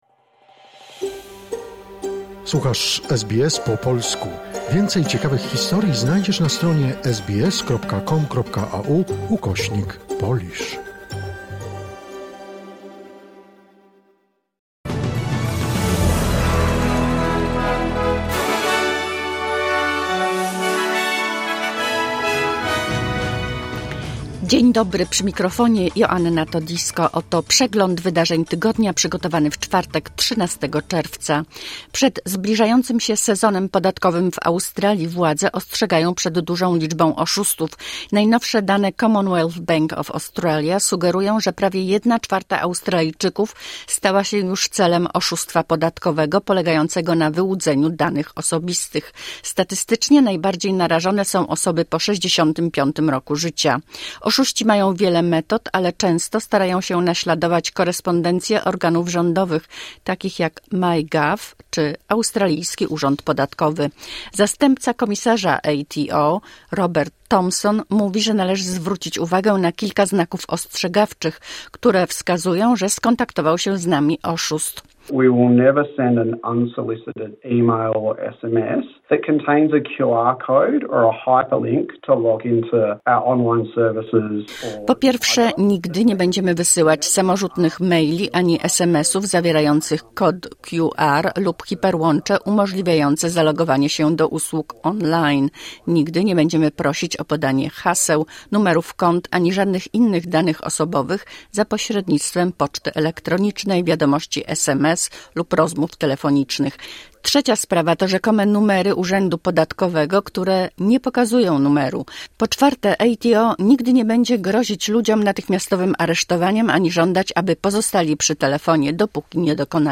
Wiadomości 13 czerwca SBS Weekly Wrap